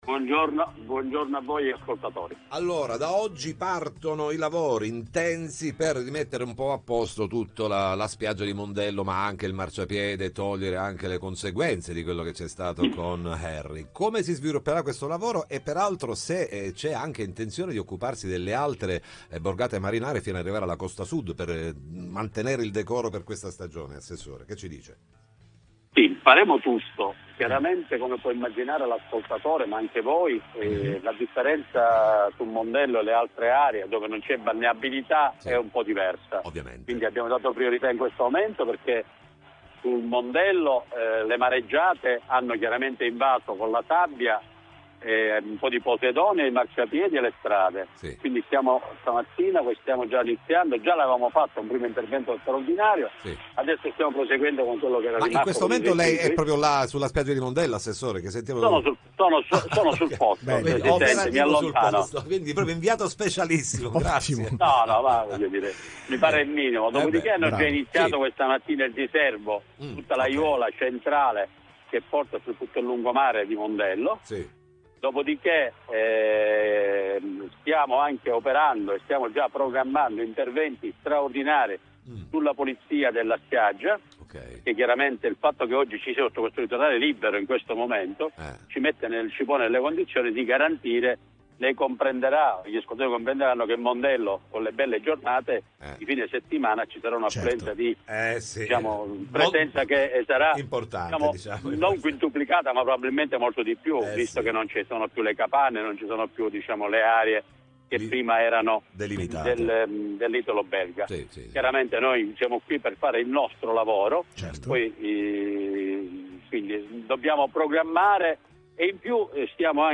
Mondello: partono oggi i lavori di pulizia, ne parliamo con l’ass. all’ambiente Pietro Alongi
Mondello: partono oggi i lavori di pulizia Interviste Time Magazine 09/03/2026 12:00:00 AM / Time Magazine Condividi: Mondello: partono oggi i lavori di pulizia, ne parliamo con l’ass. all’ambiente Pietro Alongi